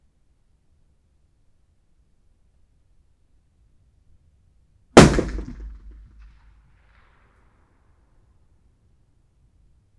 Environmental
Streetsounds
Noisepollution
UrbanSoundsNew / 01_gunshot /shot556_74_ch01_180718_163459_43_.wav